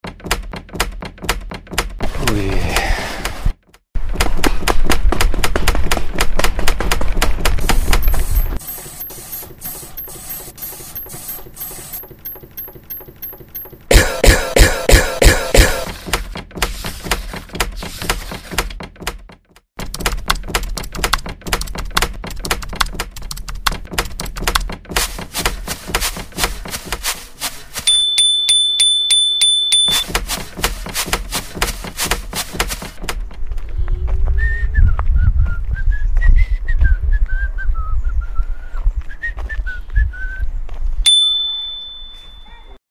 Klang-Collagen :: Remixe aus Geräuschen
Rund ums Radio waren die Teilnehmenden auf Geräusche-Fang und haben die selbst aufgenommenen Geräusche in einer Klang-Collage zu einem Remix verarbeitet.
Das Rascheln der Blätter im Wald, das Schlürfen von Schuhen auf dem Kies, das Rennen auf der Rampe, das Pollern auf dem Garagentor - all das schärft die Wahrnehmung und die sprachliche Ausdrucksfähigkeit.
Ob gleichzeitig oder nacheinander, ob verfremdet oder original - es sind hörbare Radiobeiträge entstanden.